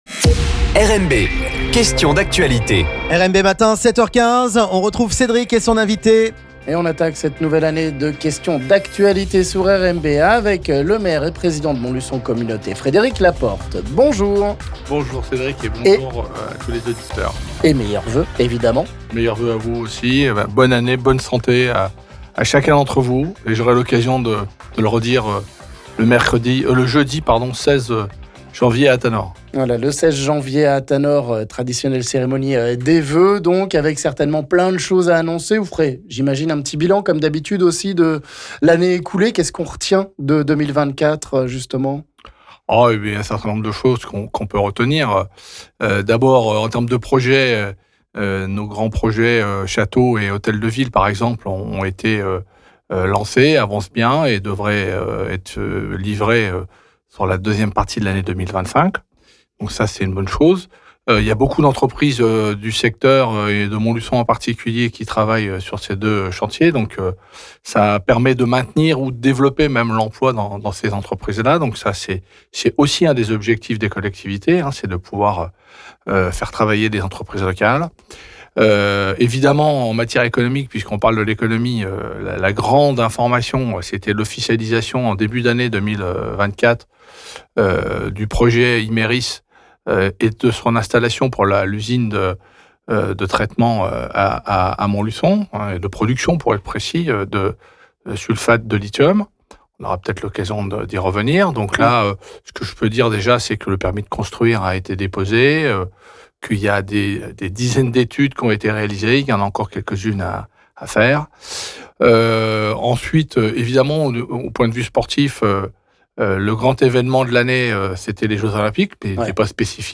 Le maire montlu�onnais et pr�sident de Montlu�on Communaut� Fr�d�ric Laporte est le 1er invit� de 2025 dans Questions d'Actualit� - L'Invit� du Jour